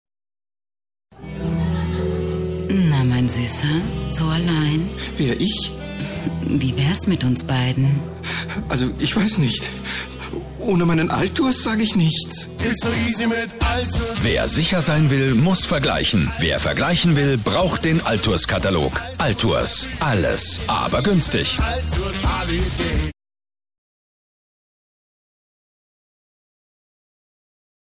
Sprecherin türkisch, Schauspielerin.
Sprechprobe: eLearning (Muttersprache):
turkish female voice over artist.